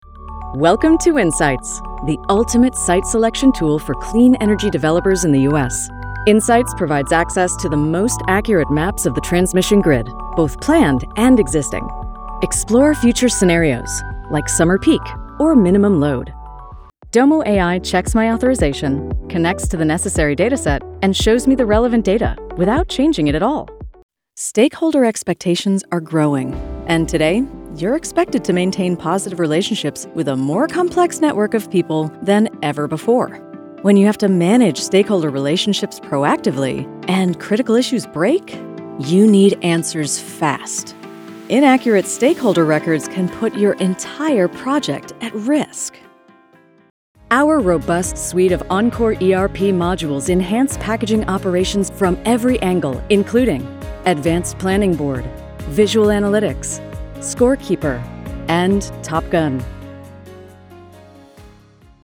Professional Female Voiceover Artist
Tech-Samples-Knowledgeable-Approachable.mp3